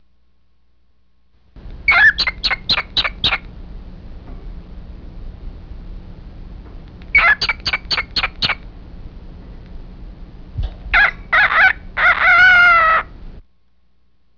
- - - - - - - - - - - - - - - - - - - - - - - - - - - - - - - - - Also: the amazing "Petey" Hear this... the rooster crows - - - - - - - - - - - - - - - - - - - - - - - - - - - - - - - - [BACK] to HOME page
pets_here chick.wav